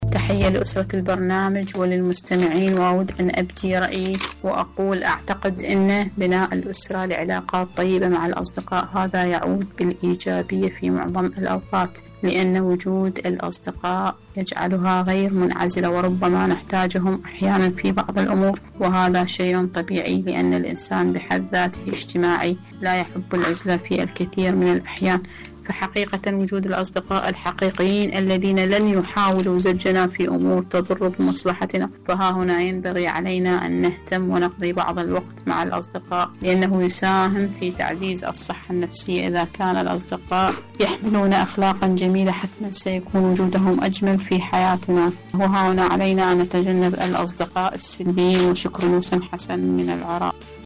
إذاعة طهران- معكم على الهواء